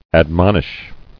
[ad·mon·ish]